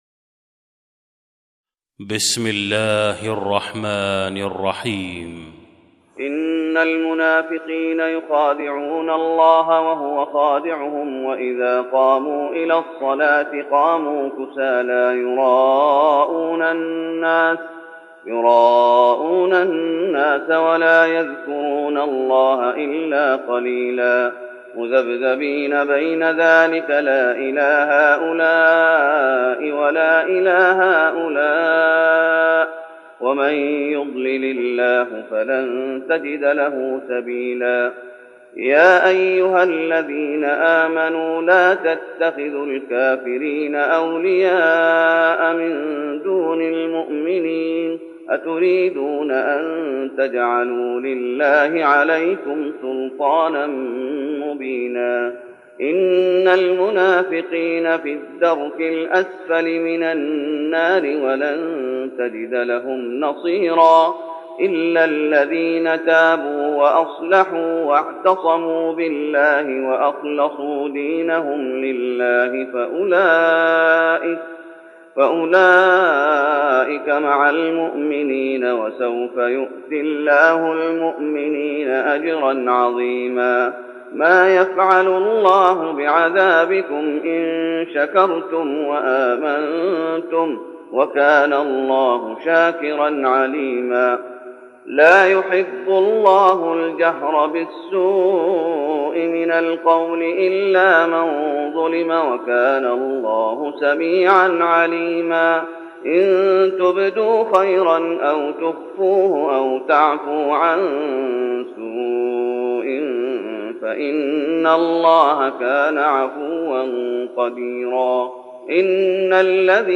تهجد رمضان 1412هـ من سورة النساء (142-176) Tahajjud Ramadan 1412H from Surah An-Nisaa > تراويح الشيخ محمد أيوب بالنبوي 1412 🕌 > التراويح - تلاوات الحرمين